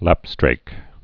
(lăpstrāk) also lap·streak (-strēk)